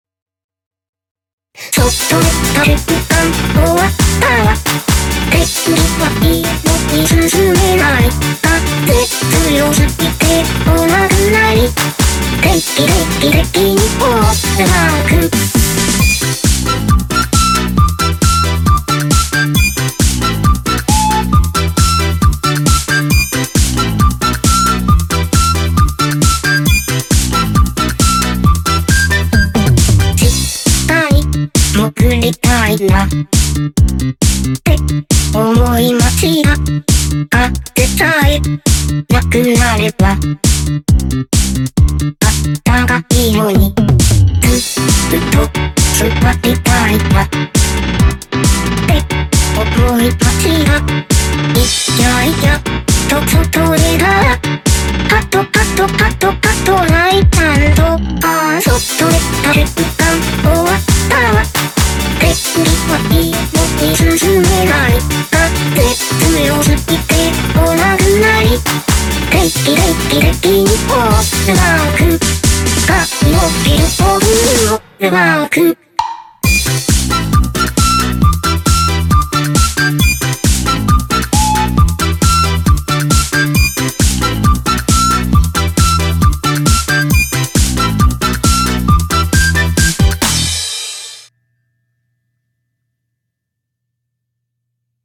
BPM135
Audio QualityPerfect (High Quality)